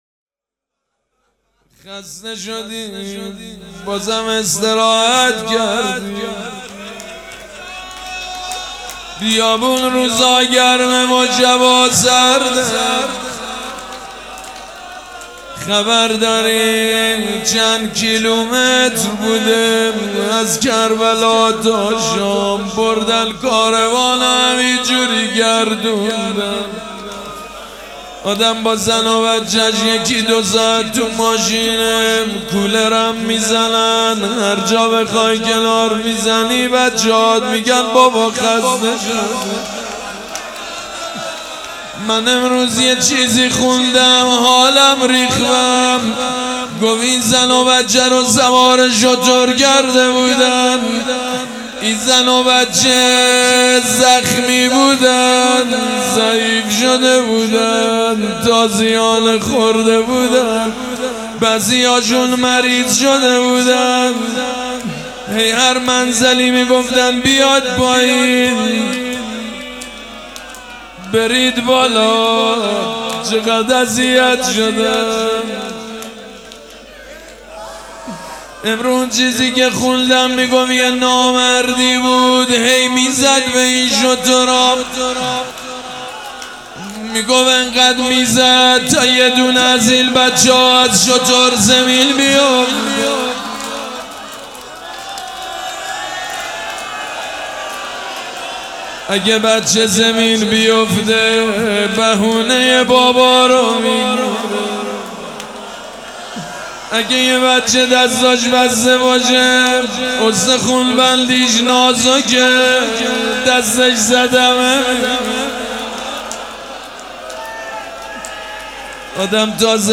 روضه
مداح
مراسم عزاداری شب اول